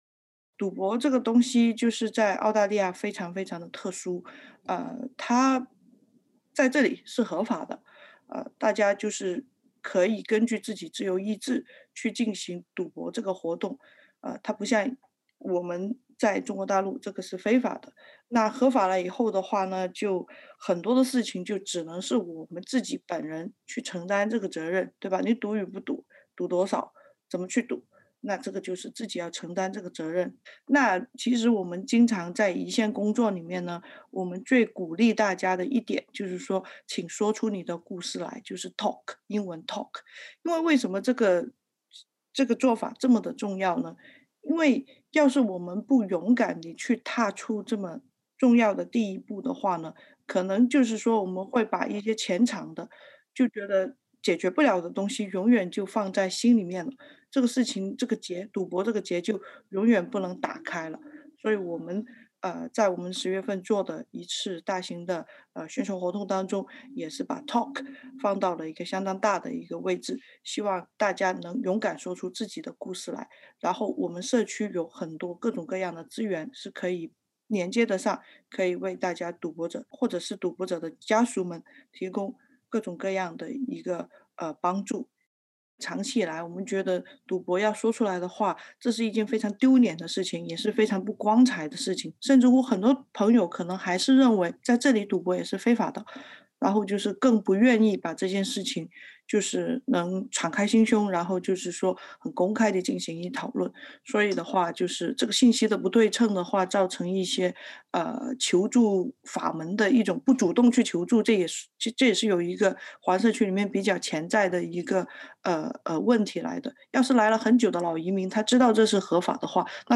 点击上方音频收听完整采访 关注更多澳洲新闻，请在Facebook上关注 SBS Mandarin ，或在微博上关注 澳大利亚SBS广播公司 。